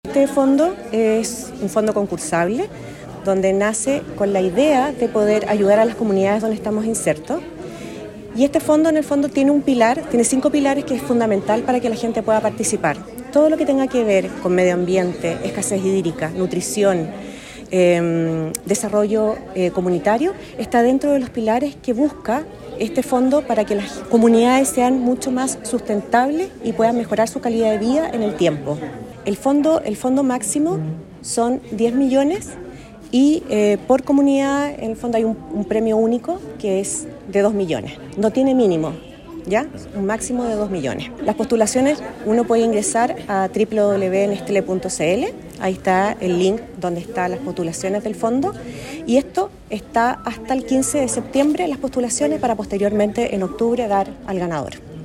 En la Sala de Sesiones de la Municipalidad de Osorno, se realizó el lanzamiento del Fondo de Desarrollo Local Henri Nestlé.